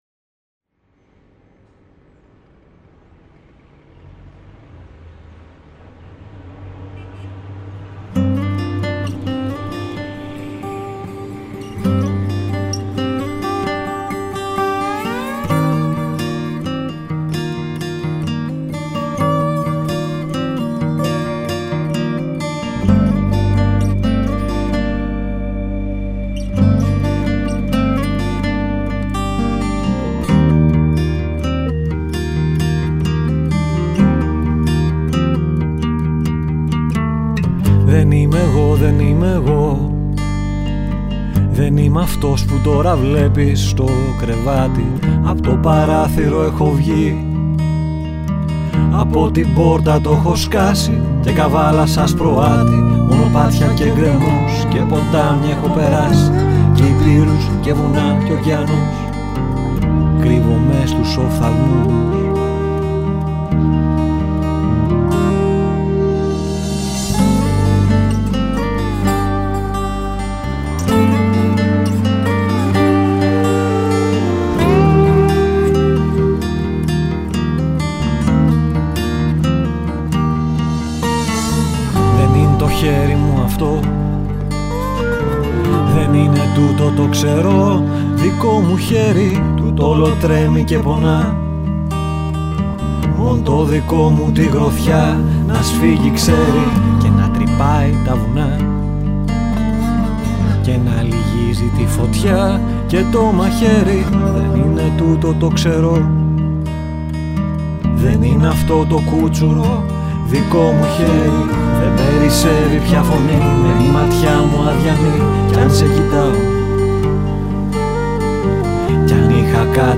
I recorded flute and slide guitar.
His lyrics are very inspired and the music has a penetrating feel to it.